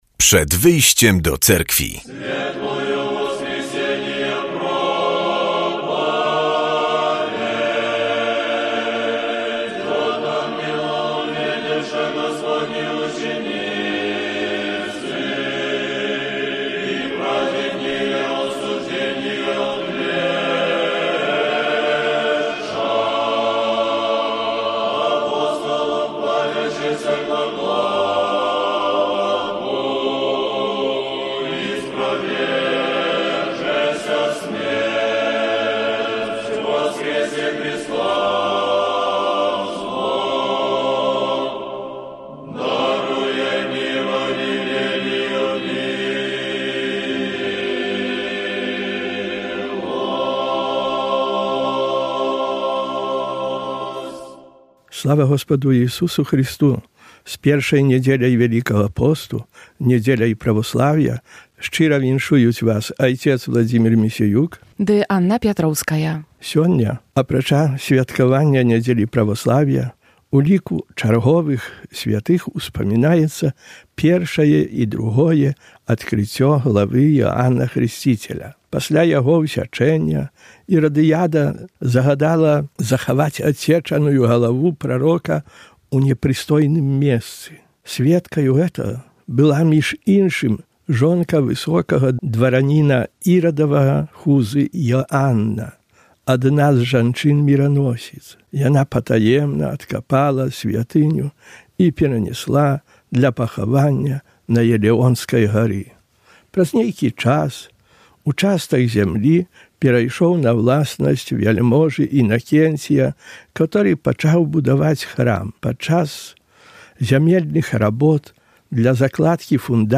W audycji usłyszymy kazanie na temat niedzielnej Ewangelii i informacje z życia Cerkwi prawosławnej. Wielki Post charakteryzuje się niepowtarzalną specyfiką odprawiania nabożeństw w cyklu całego roku liturgicznego.